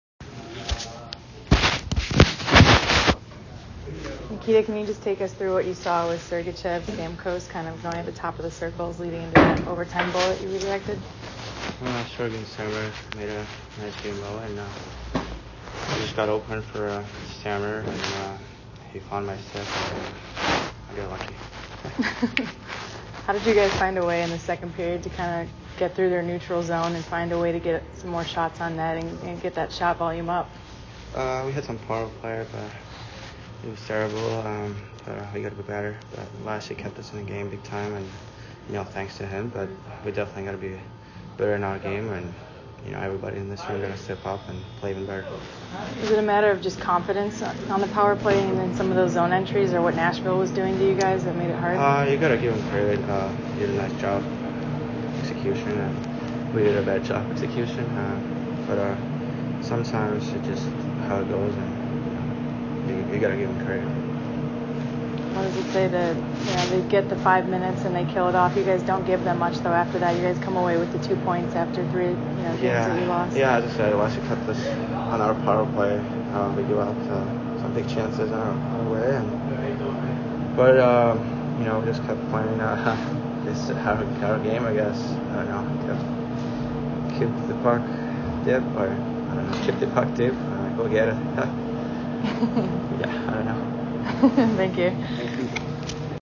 Nikita Kucherov Post - Game Dec. 3 At Nashville